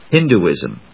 Hin・du・ism /hínduːìzm/